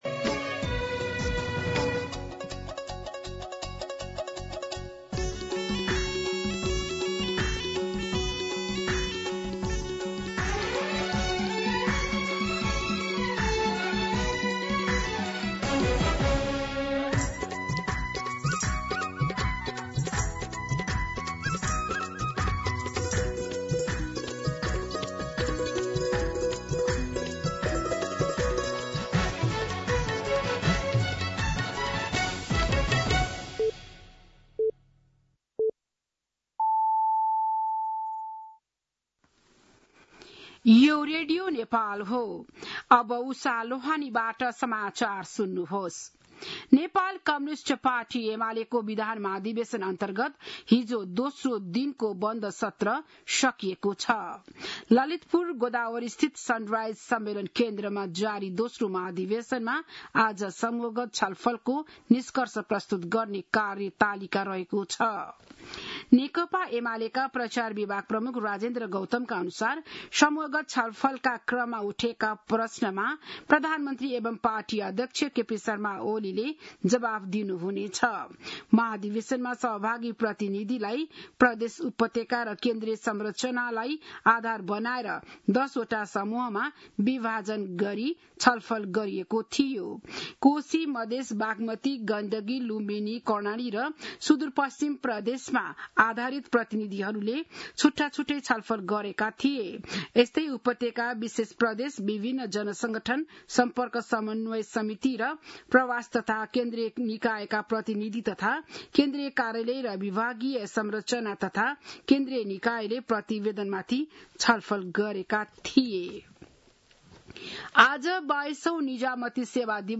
बिहान ११ बजेको नेपाली समाचार : २२ भदौ , २०८२
11-am-News-1-1.mp3